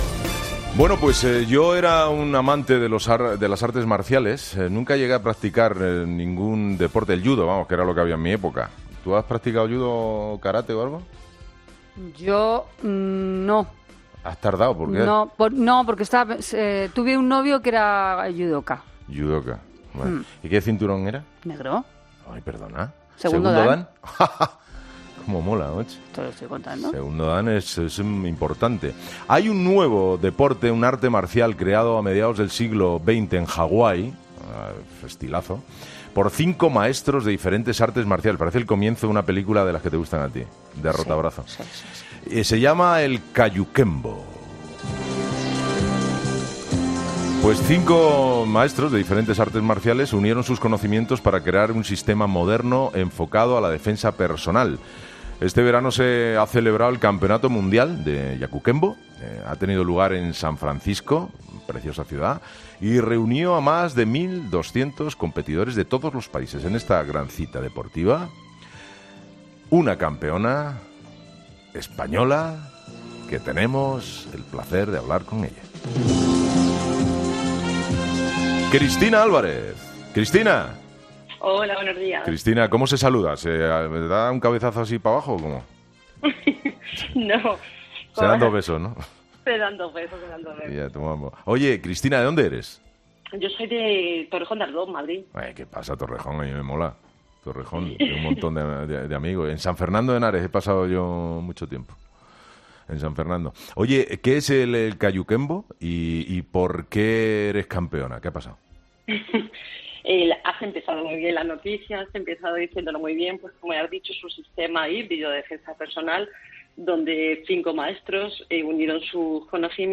Para conocer todos los detalles, este miércoles ha sido entrevistada en 'Herrera en COPE', donde ha explicado qué es el Kajukenbo , la disciplina que practica, y que puede definirse como un arte marcial híbrido que combina las técnicas del karate, del Judo y del boxeo.